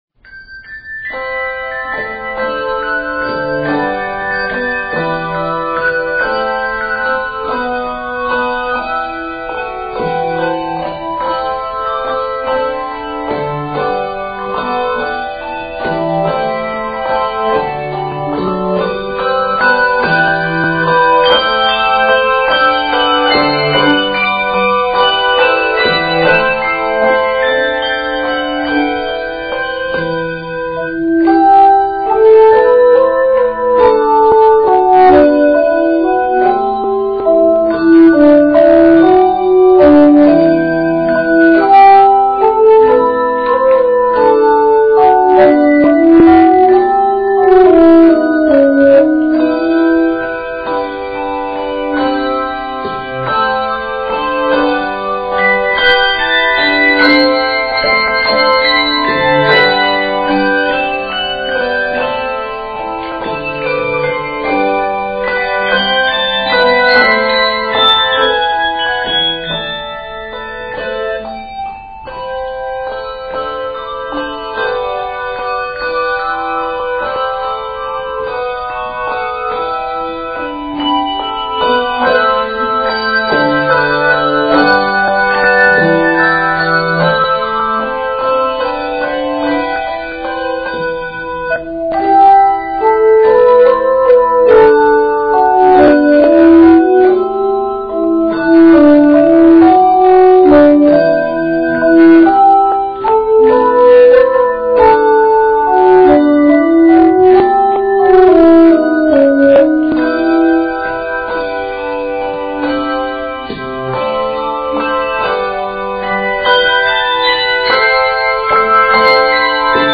that beautiful and wistful tune